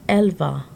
Click each word to hear the pronunciation.